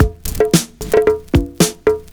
112PERCS01.wav